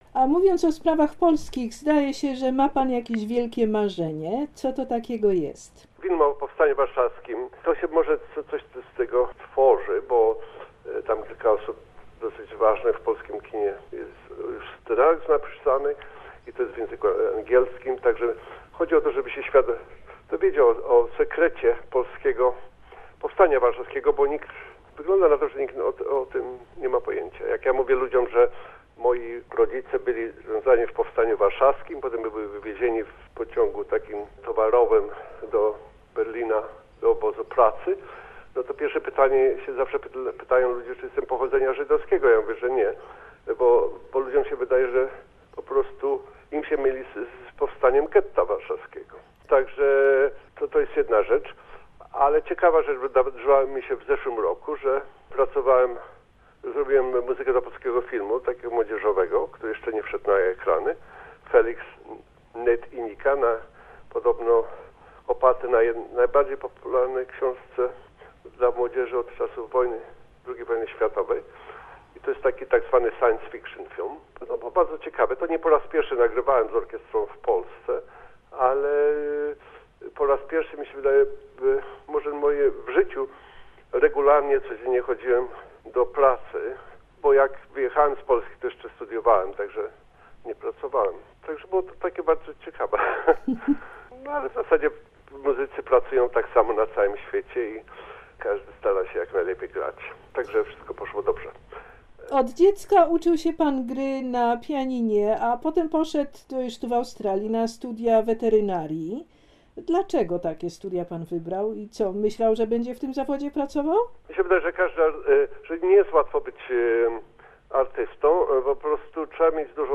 W czasie rozmowy telefonicznej Pan Cezary opowiadał o swoich najnowszych kompozycjach filmowych, o nagrodach i o swoich planach na przyszłość.